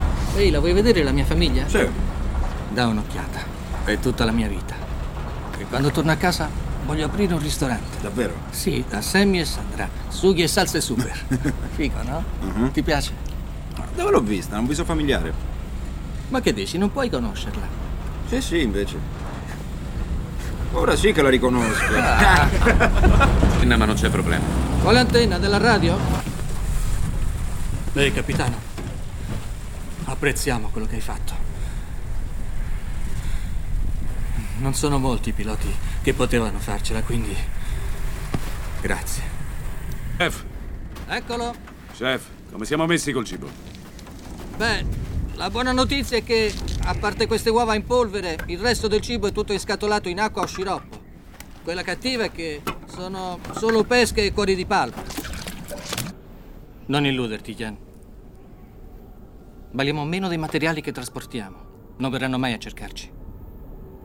nel film "Il volo della Fenice", in cui doppia Jacob Vargas.
FILM CINEMA